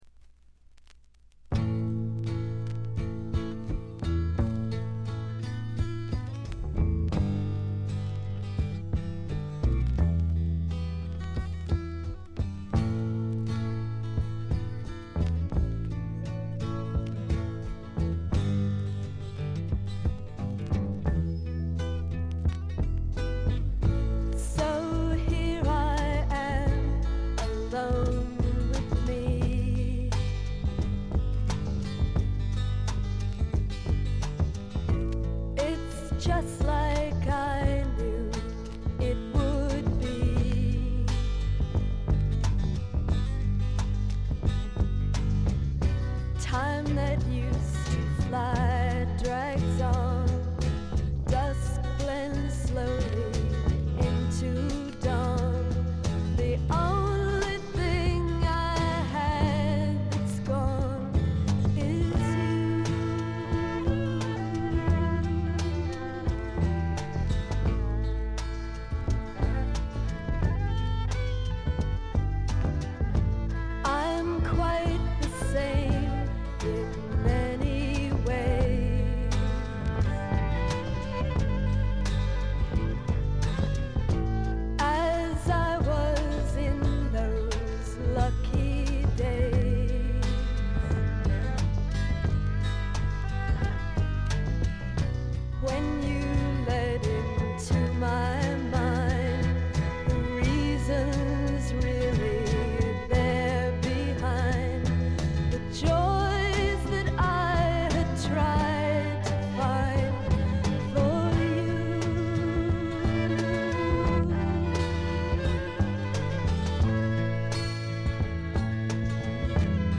ほとんどノイズ感無し。
特にB5は曲も演奏も素晴らしいアシッド・フォークの名曲です！
試聴曲は現品からの取り込み音源です。